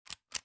reload_shotgun.ogg